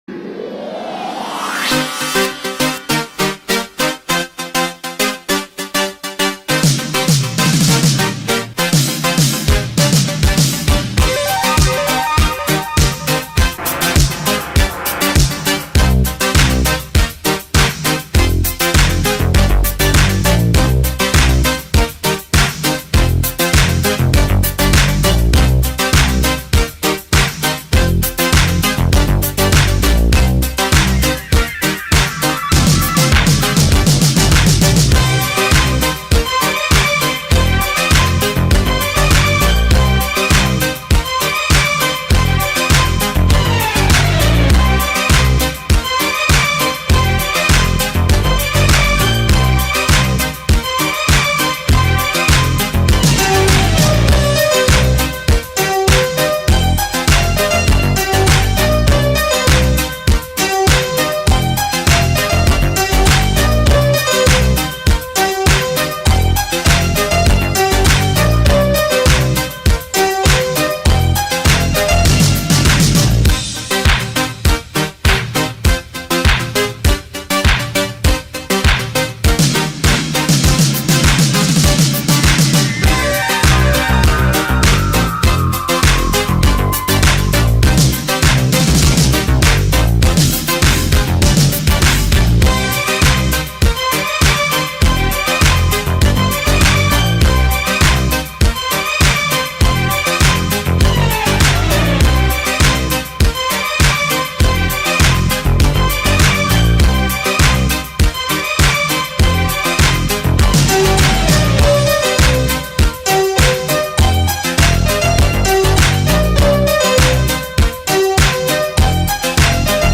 KEEflZ4B3Y4_putin-caminando-con-musica-de-fondo-version-completa.mp3